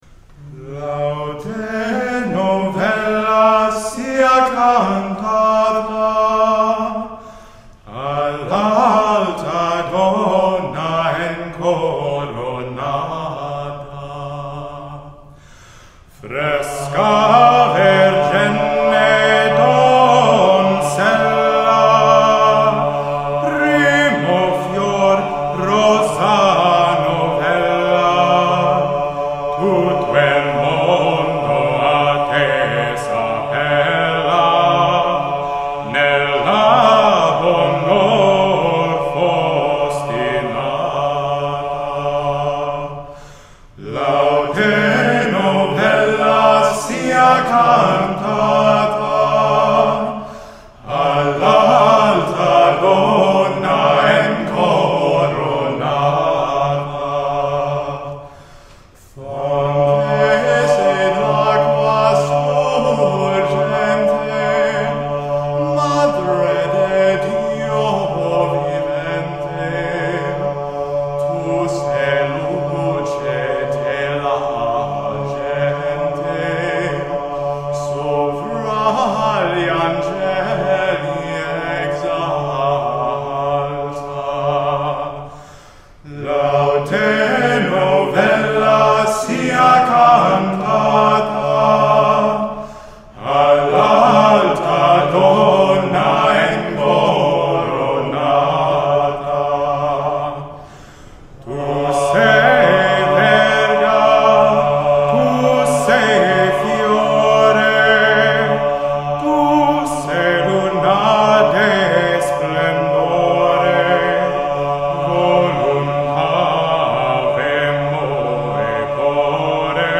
Lauda